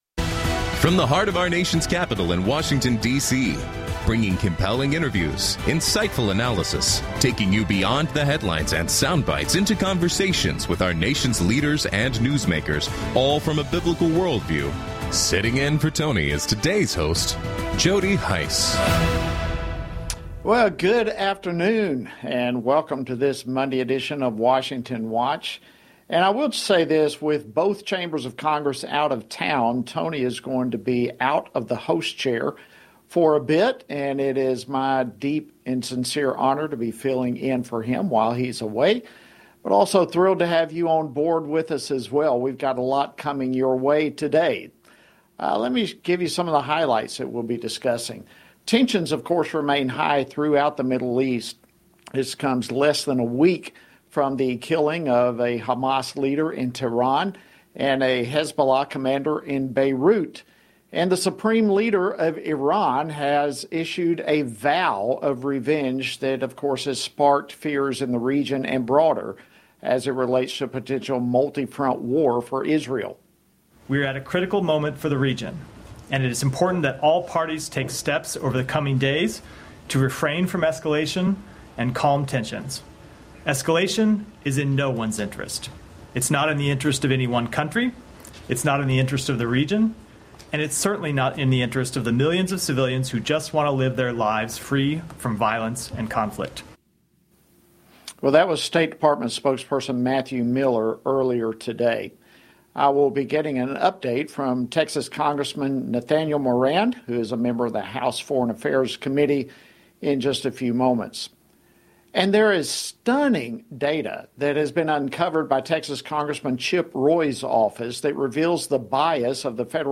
On today’s program: Nathaniel Moran, U.S. Representative for the 1st District of Texas, responds to tension in the Middle East as the threat of a regional war against Israel remains high. Chip Roy, U.S. Representative for the 21st District of Texas, reveals data his office uncovered demonstrating the Biden administration’s weaponization of the Department of Justice against pro-life activists. Brian Babin, U.S. Representative for the 36th District of Texas, reacts to the Biden administration suspending a mass-parole program that flew migrants from Cuba, Haiti, Nicaragua, and Venezuela into the U.S. following the documentation of widespread fraud and abuse.